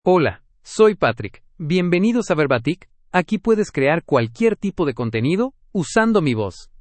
Patrick — Male Spanish (United States) AI Voice | TTS, Voice Cloning & Video | Verbatik AI
MaleSpanish (United States)
Voice sample
Male
Patrick delivers clear pronunciation with authentic United States Spanish intonation, making your content sound professionally produced.